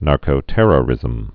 (närkō-tĕrə-rĭzəm)